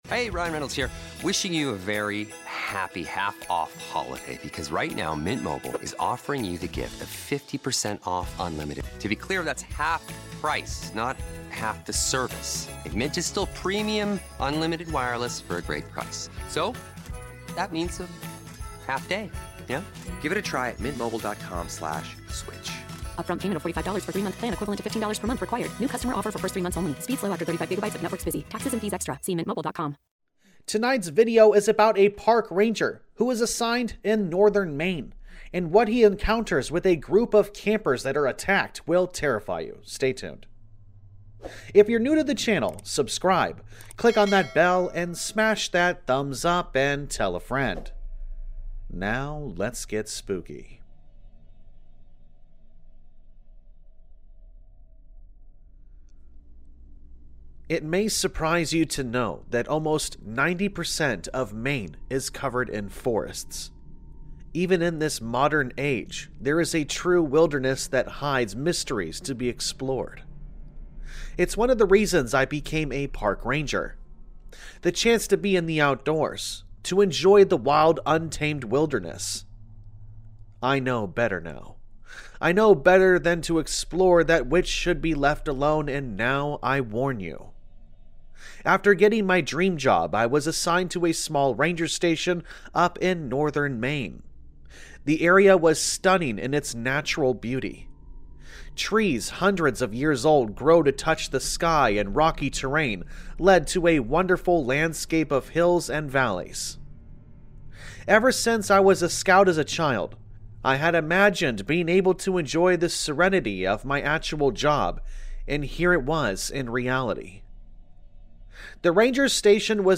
All Stories are read with full permission from the authors: